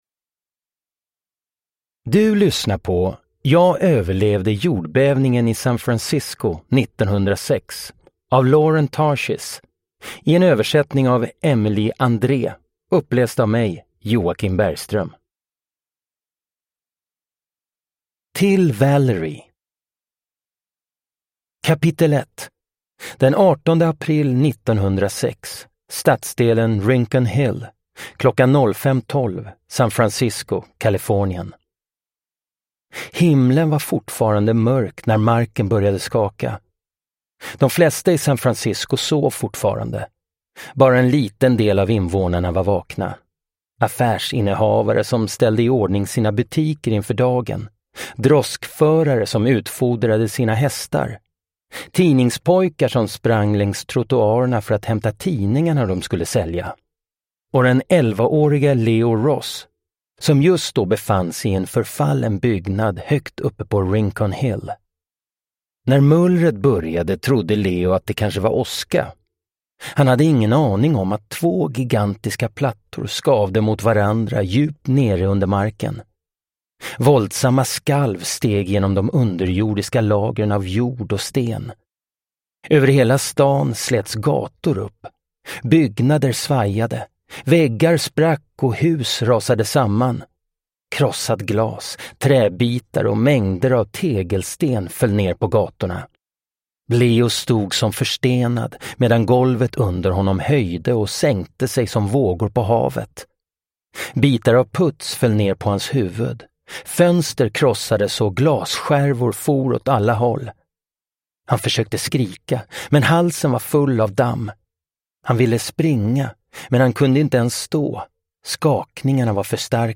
Jag överlevde jordbävningen i San Francisco 1906 (ljudbok) av Lauren Tarshis